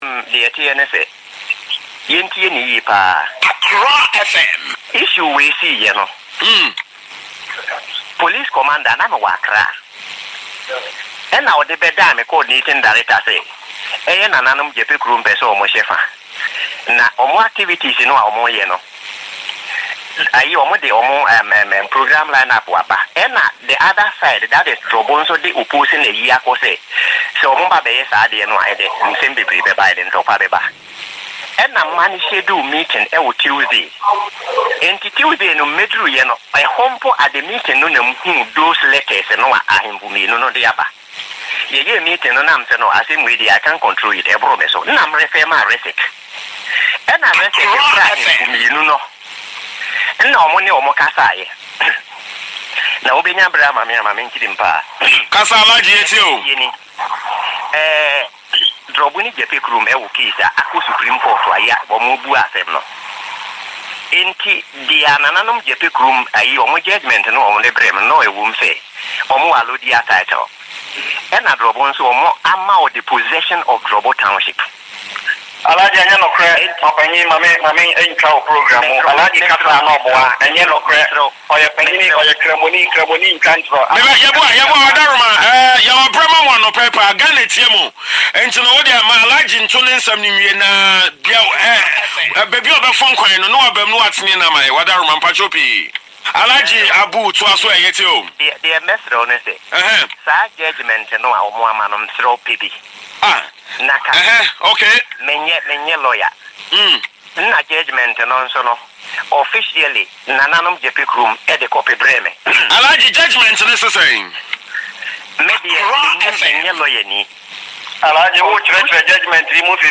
Abuu was warned of an impending threat and yet he refused to act – even if the issue was above him as he claimed in the interview on Accra FM, he could have referred the matter to the Regional Security Council (REGSEC).
Alhaji_Abuu_Jaman_South_MCE_Accra_FM.mp3